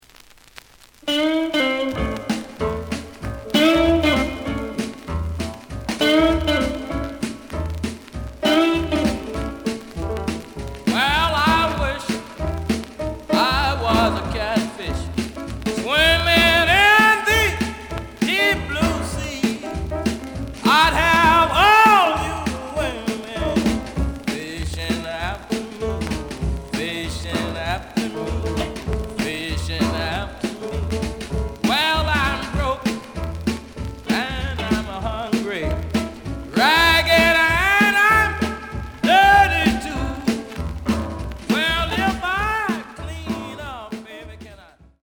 試聴は実際のレコードから録音しています。
●Genre: Rhythm And Blues / Rock 'n' Roll
●Record Grading: VG (盤に若干の歪み。プレイOK。)